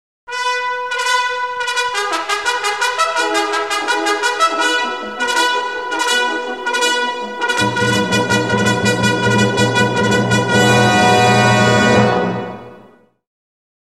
voorbeeld_trompet
voorbeeld_trompet.mp3